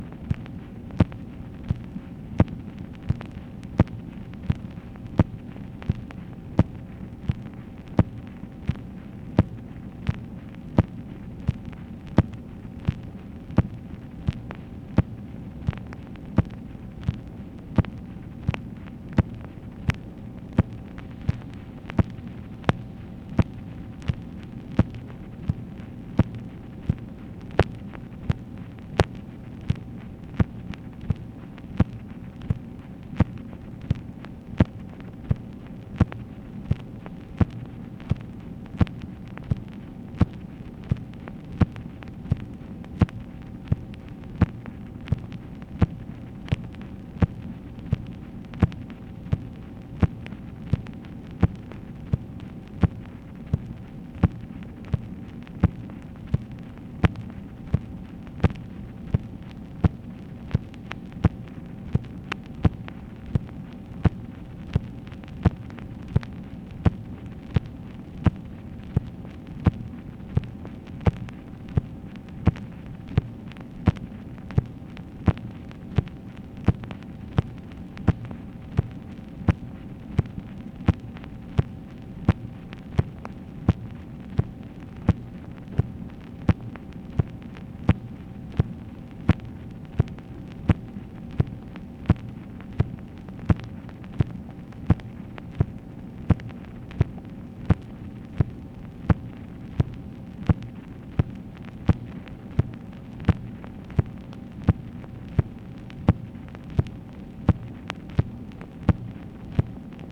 MACHINE NOISE, January 4, 1966
Secret White House Tapes | Lyndon B. Johnson Presidency